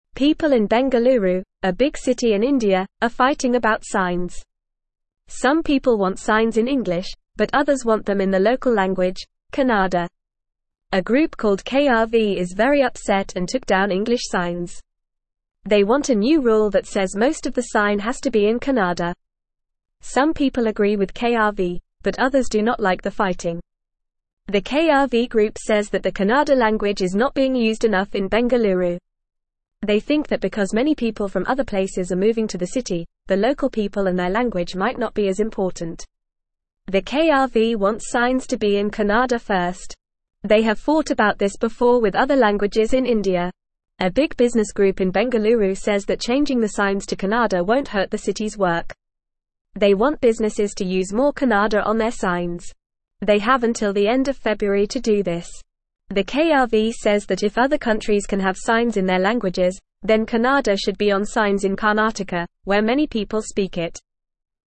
Fast
English-Newsroom-Lower-Intermediate-FAST-Reading-People-in-India-want-signs-in-their-language.mp3